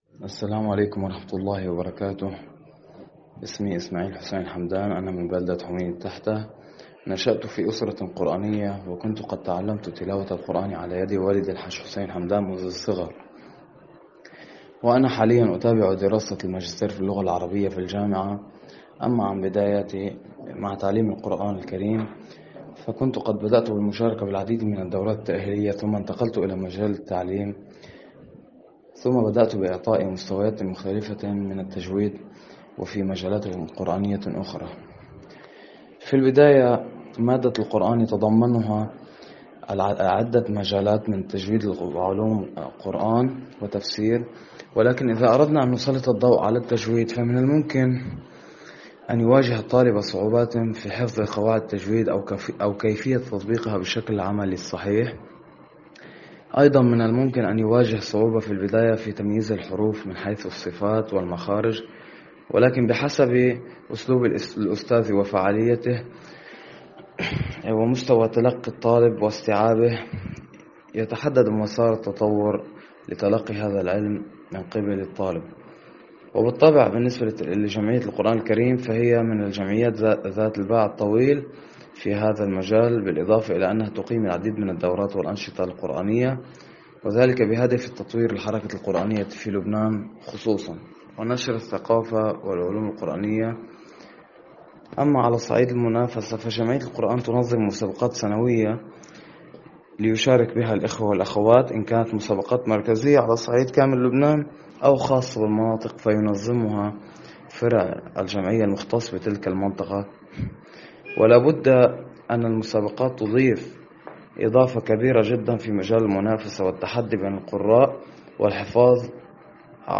قارئ لبناني لـ"إکنا":